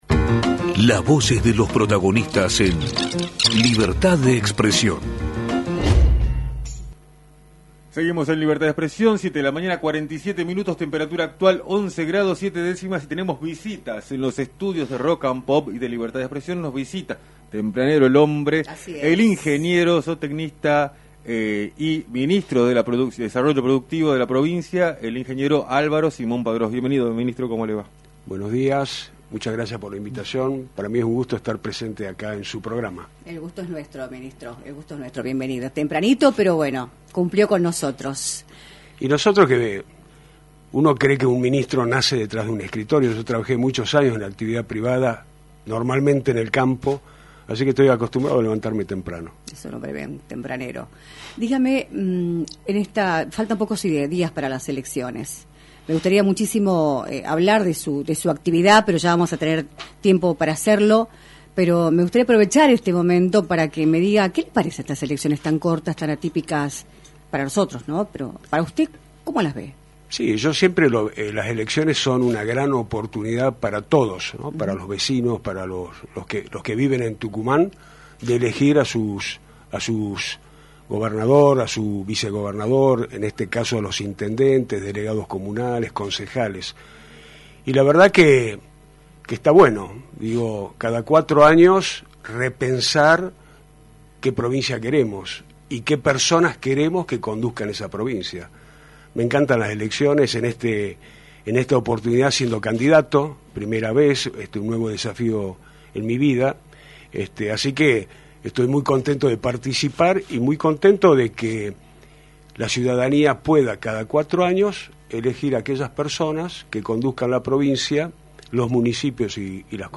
El Ministro de Desarrollo Productivo y candidato a Concejal en Yerba Buena por el oficialismo, Álvaro Simón Padrós, visitó los estudios de “Libertad de Expresión”, por la 106.9, para analizar el panorama electoral de la provincia, en la previa de los comicios del próximo 14 de mayo.